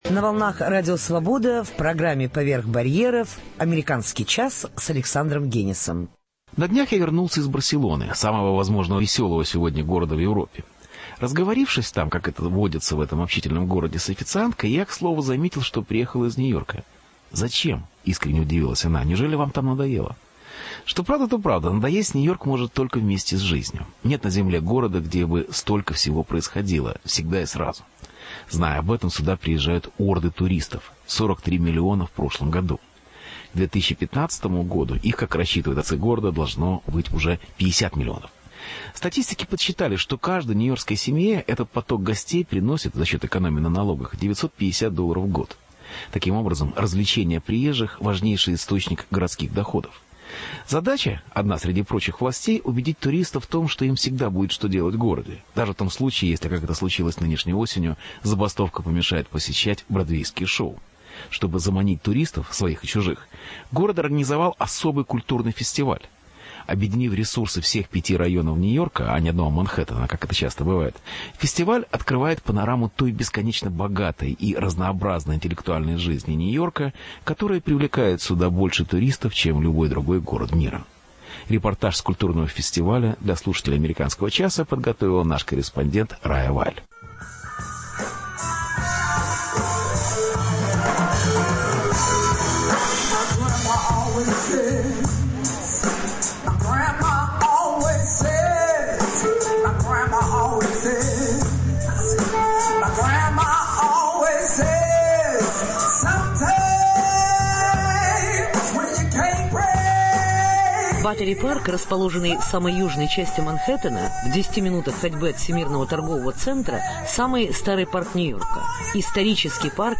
Репортаж с Фестиваля нью-йоркской культуры